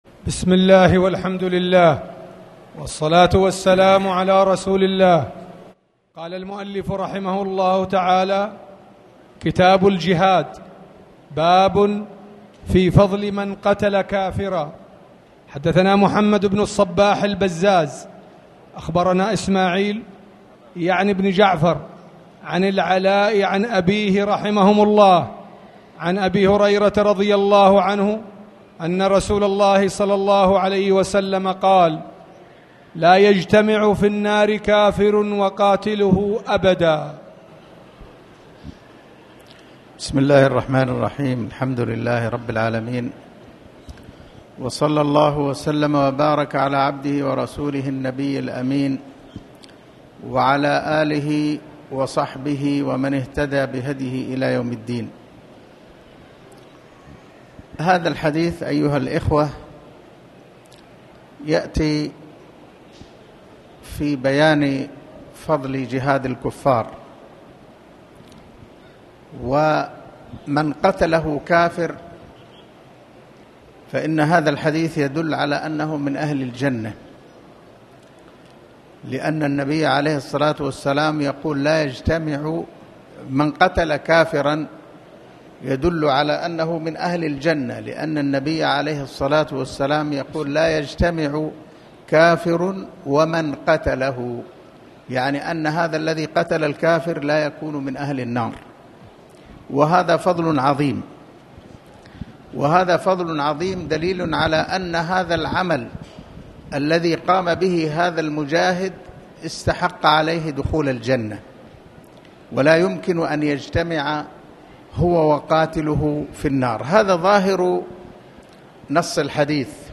تاريخ النشر ٢١ شوال ١٤٣٨ هـ المكان: المسجد الحرام الشيخ